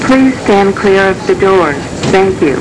Almost Every 7k Announcement
The ones marked "Breda" were used on the Rohr and non-rehabbed Breda cars, i.e. the 1000 through 4000-Series, and were rolled out to those cars around 1996.